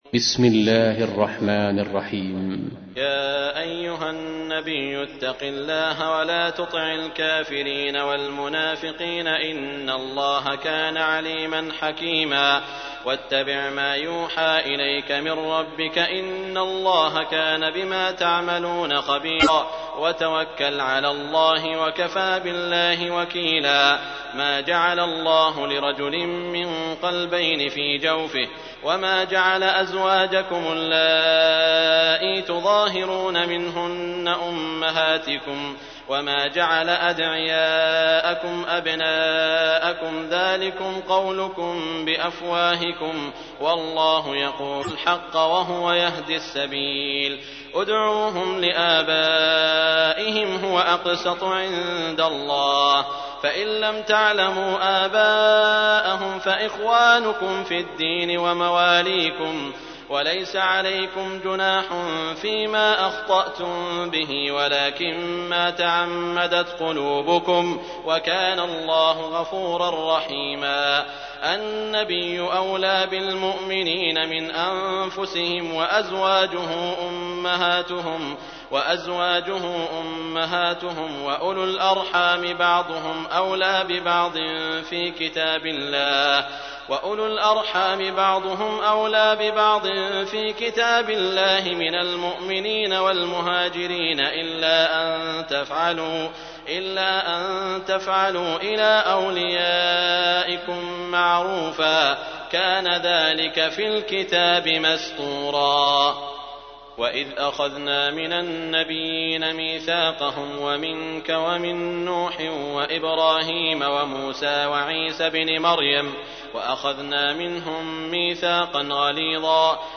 تحميل : 33. سورة الأحزاب / القارئ سعود الشريم / القرآن الكريم / موقع يا حسين